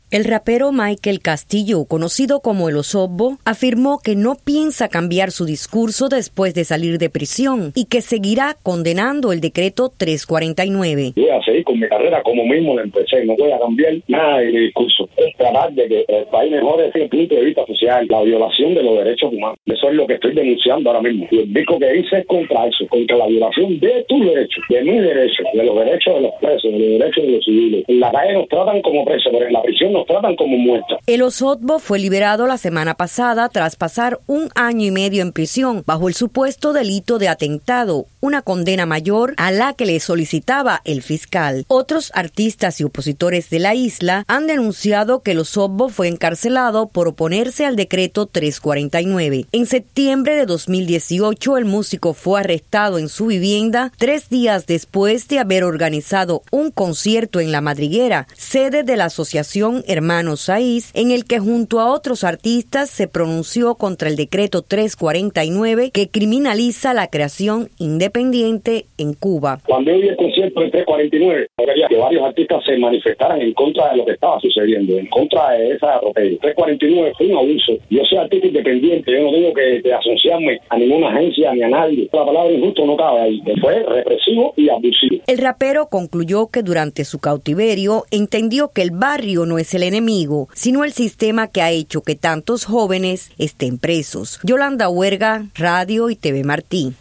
El rapero cubano Maykel Castillo "El Osorbo" dijo este lunes que no piensa cambiar su discurso después de salir de prisión y que seguirá condenando el decreto 349. En entrevista con RadioTelevisión Martí el artista aseguró que va a seguir con su carrera pero no va a cambiar su discurso con el...